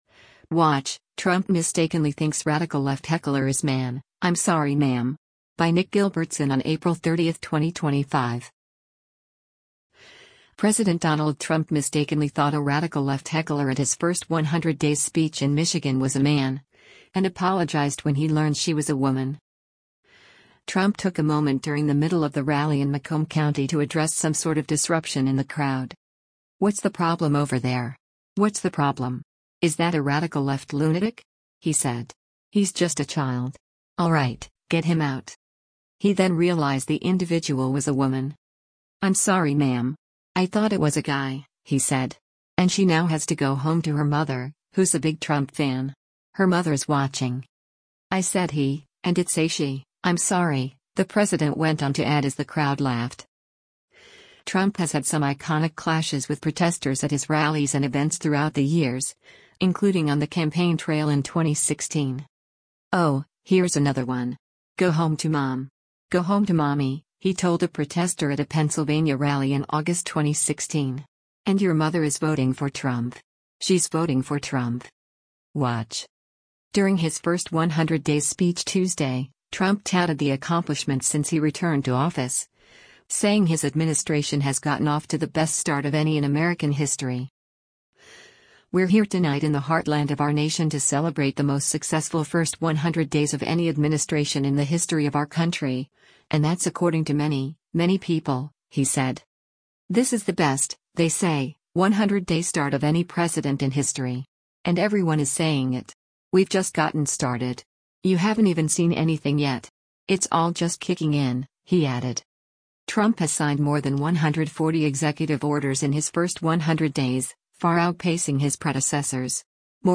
Trump took a moment during the middle of the rally in Macomb County to address some sort of disruption in the crowd.
“I said he, and it’s a she, I’m sorry,” the president went on to add as the crowd laughed.